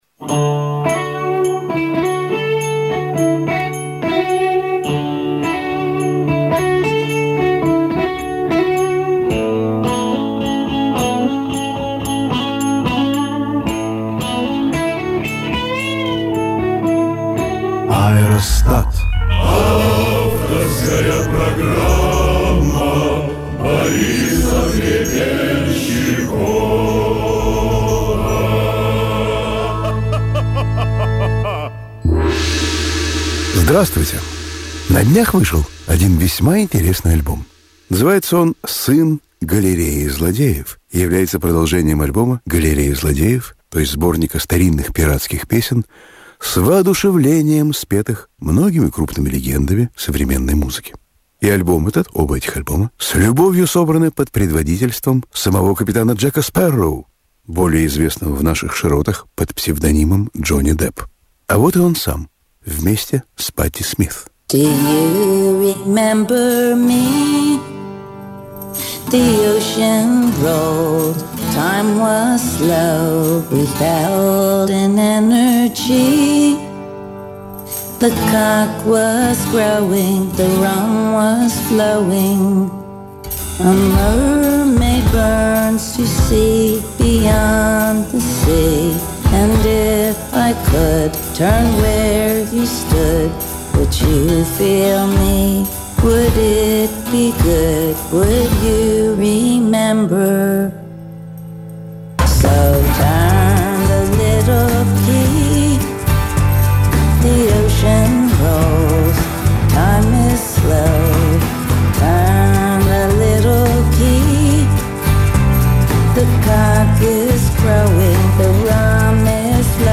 Про пиратов и пиратские песни. Рассказывает БОРИС Б.ГРЕБЕНЩИКОВ.